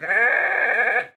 sounds / mob / goat / pre_ram4.ogg